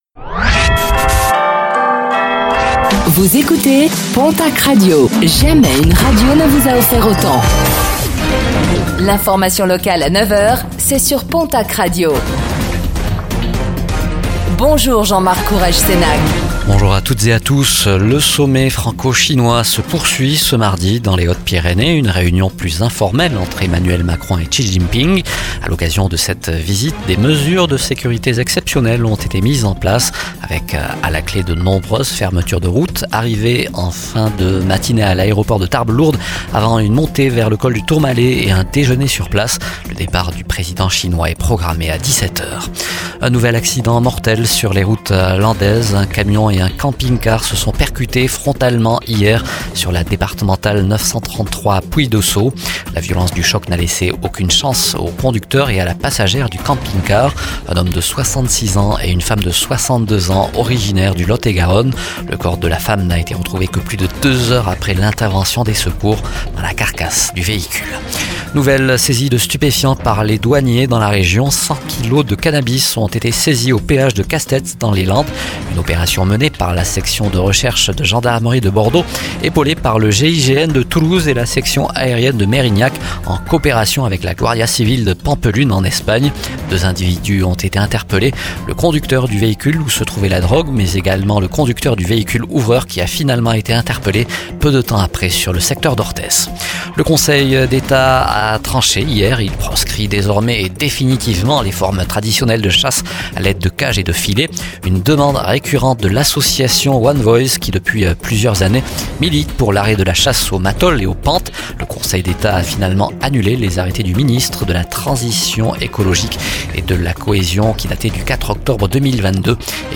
Réécoutez le flash d'information locale de ce mardi 07 mai 2024